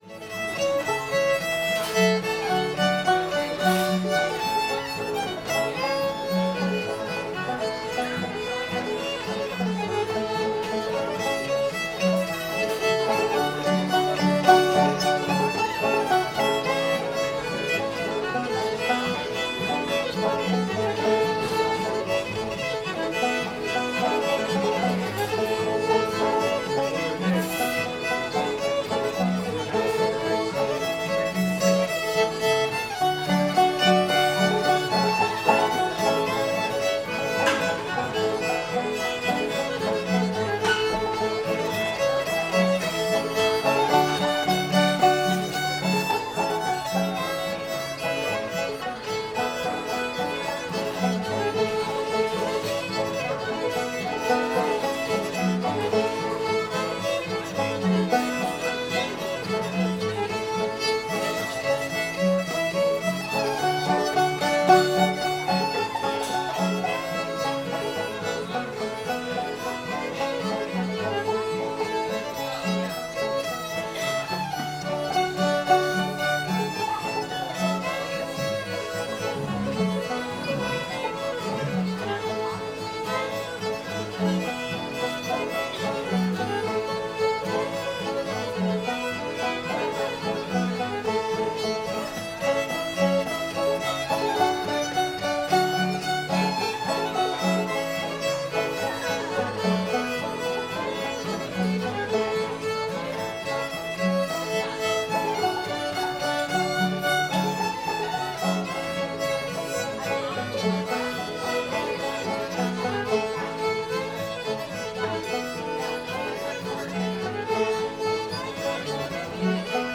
wish i had my time again [A]